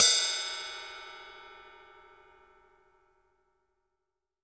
Index of /90_sSampleCDs/AKAI S6000 CD-ROM - Volume 3/Drum_Kit/ROCK_KIT2